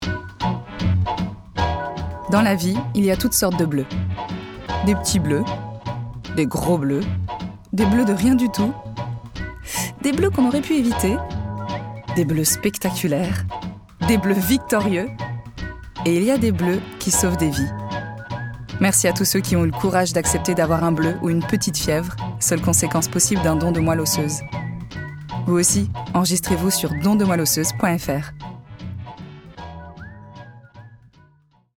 Voix off
CHANT moderne et lyrique Mezzo-Soprano